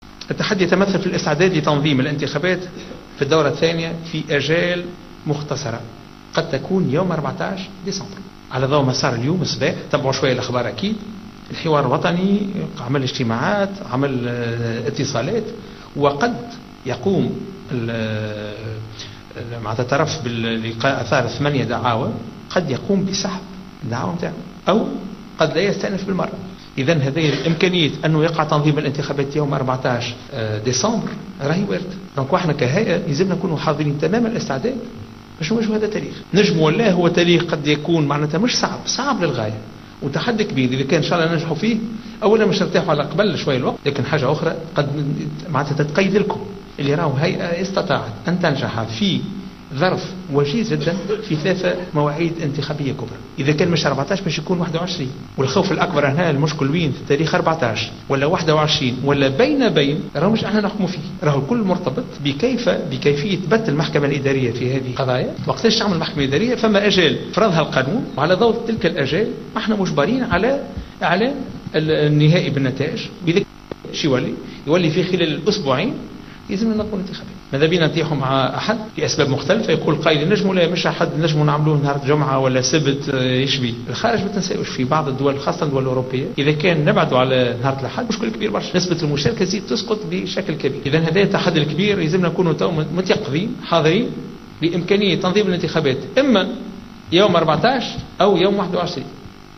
Dans une déclaration accordée à Jawhara FM, le président de l’Instance Supérieure Indépendante pour les Elections (ISIE), Chafik Sarsar, a annoncé que le deuxième tour de l’élection présidentielle pourrait avoir lieu le 14 décembre 2014.